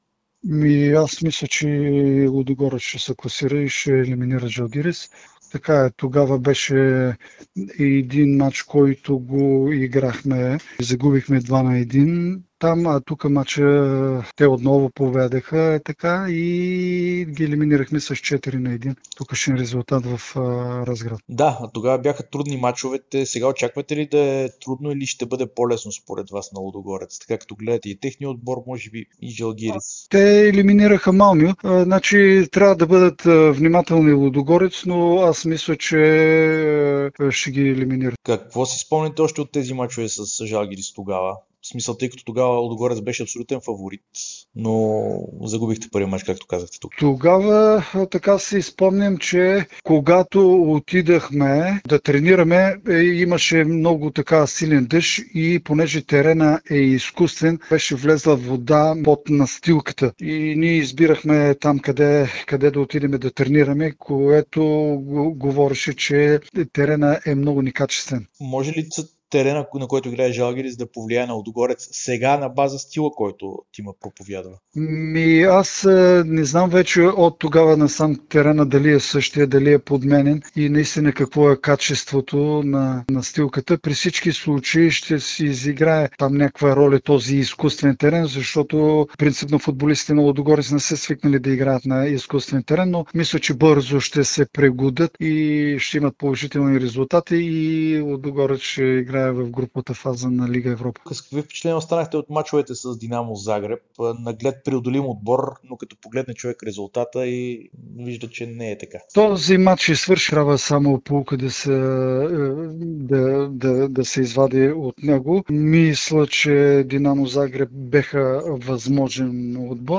Бившият треньор на Лудогорец Георги Дерменджиев даде интервю пред Дарик и dsport, в което говори за шансовете на "орлите" да преодолят Жалгирис и да влязат в групите на Лига Европа.